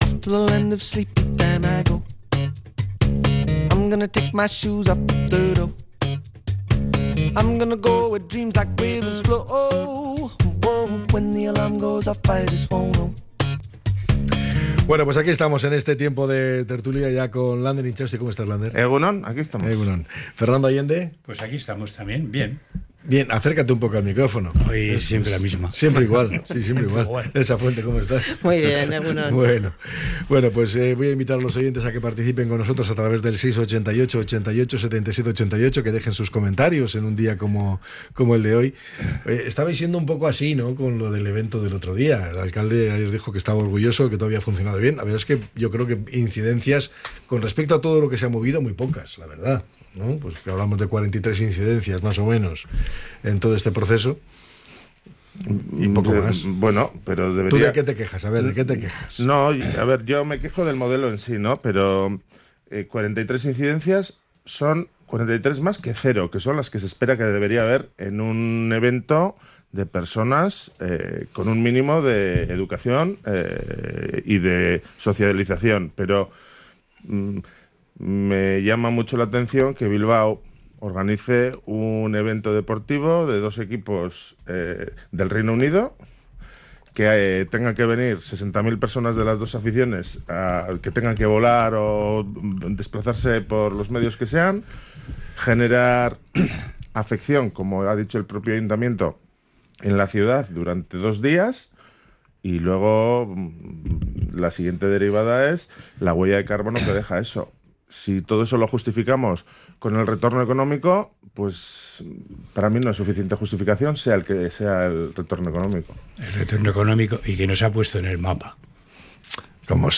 La tertulia 23-05-25.